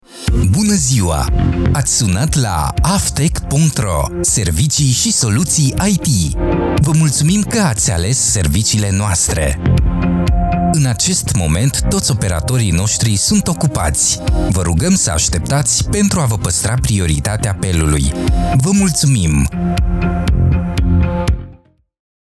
IVR
Natural and energetic style can breathe life into any script!
Styles: New, Contemporary, Clear, Deep, Hard Sell, Soft Sell, Sincere, Engaging, Urban